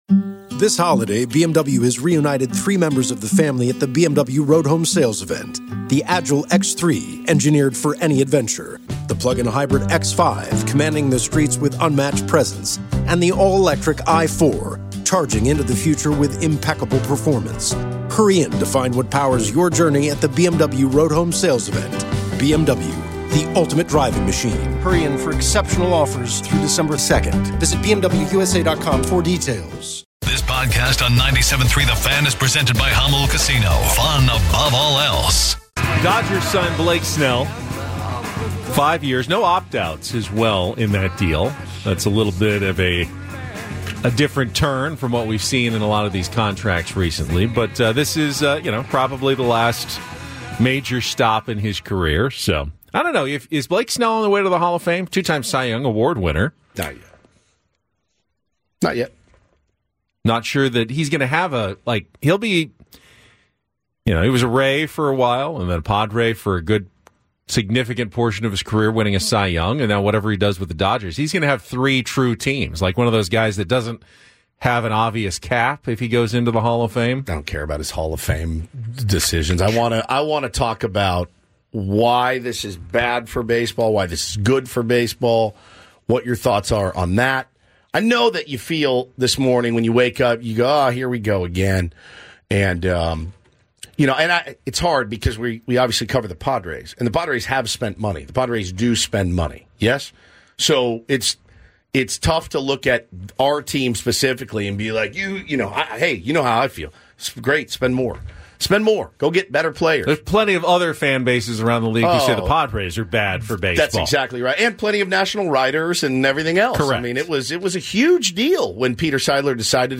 Listen here for big moments from the show, weekdays, 6AM - 10AM PT on 97.3 The Fan.